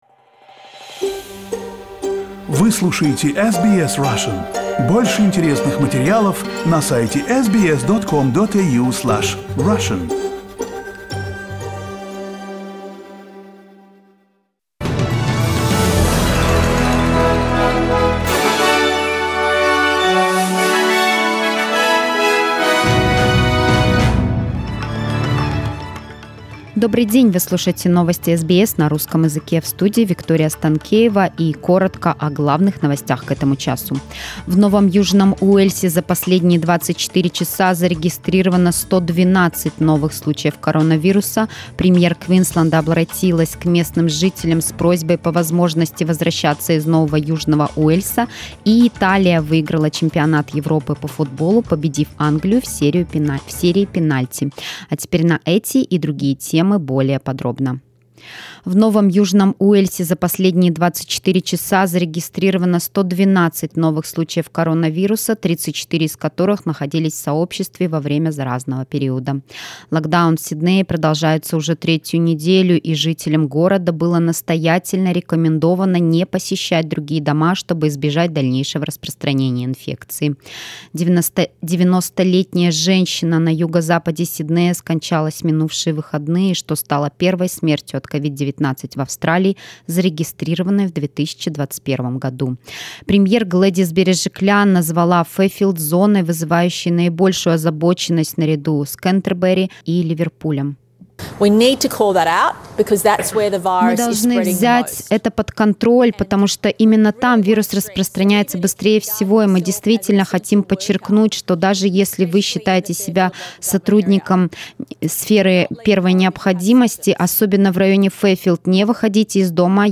Новости SBS на русском языке - 12.07